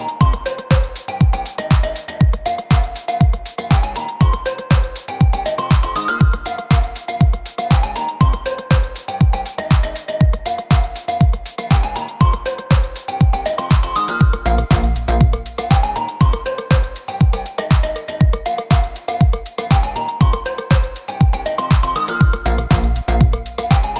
no Backing Vocals Dance 5:39 Buy £1.50